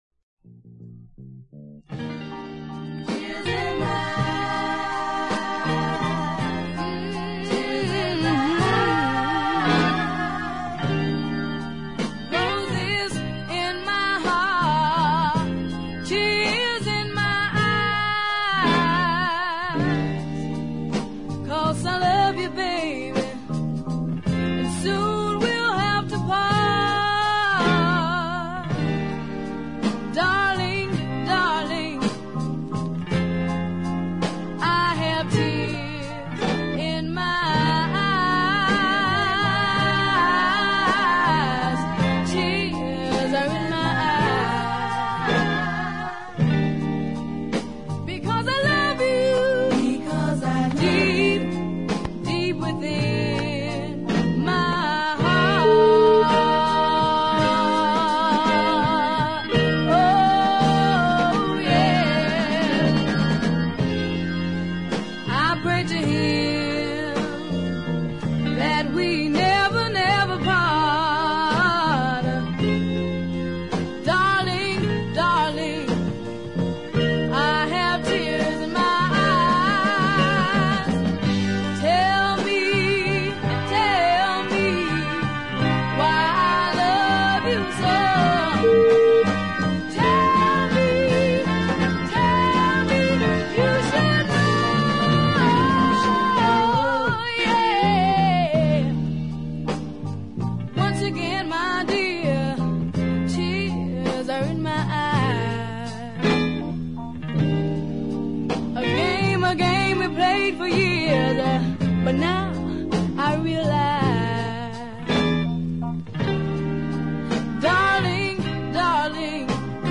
is a little too “teen beat” for my taste but the ballad flip